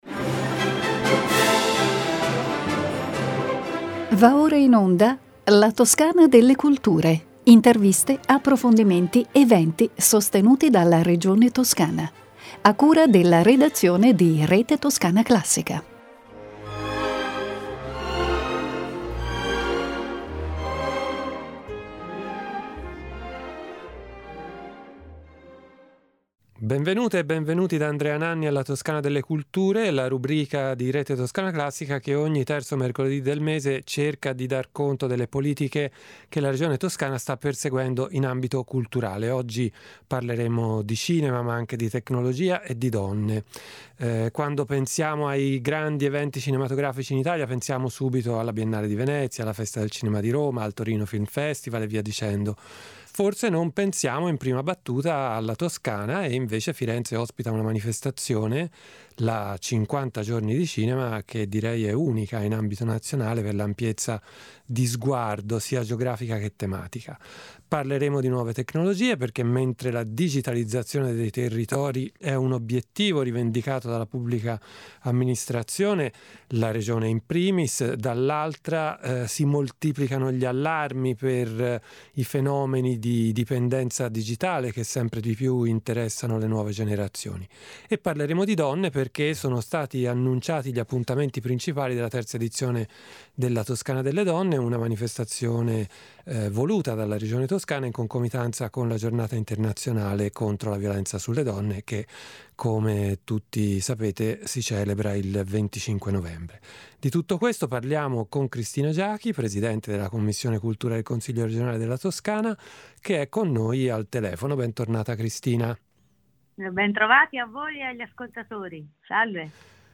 Di tutto questo parliamo con Cristina Giachi , Presidente della Quinta commissione del Consiglio regionale della Toscana .